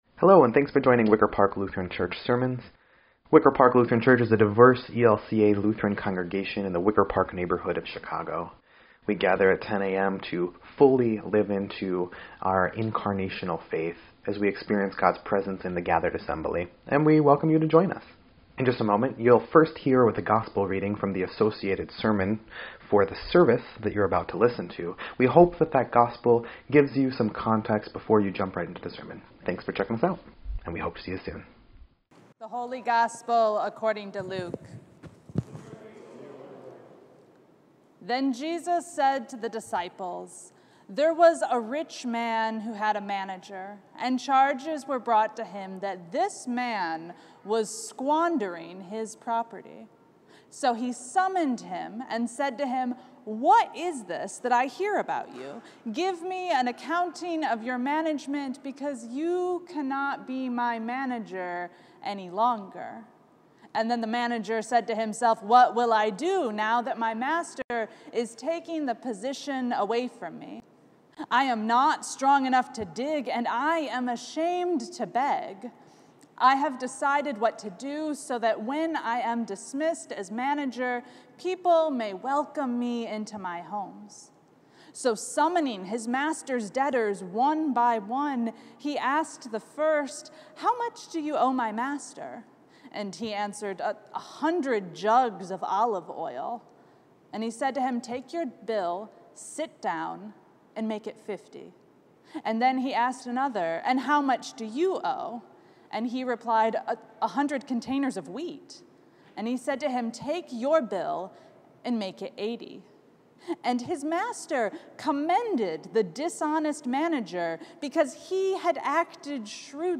9.18.22-Sermon_EDIT.mp3